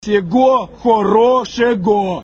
позитивные
добрые
голосовые